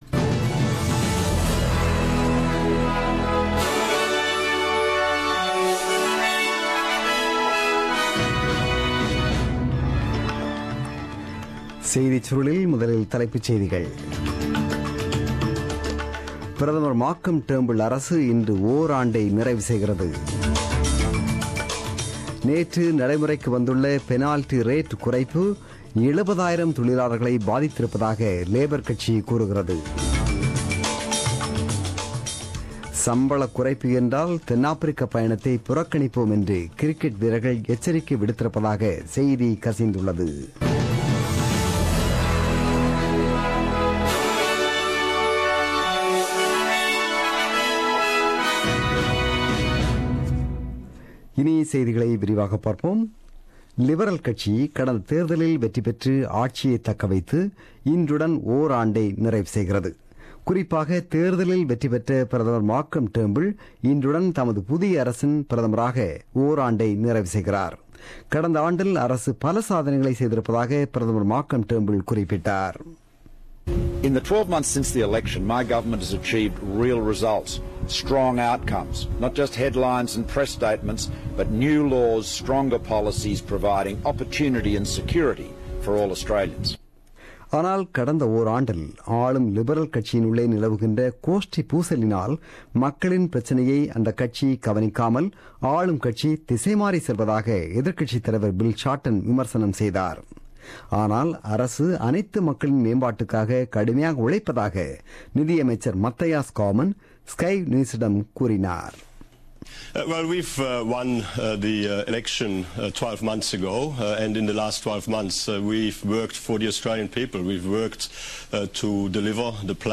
The news bulletin broadcasted on 2 July 2017 at 8pm.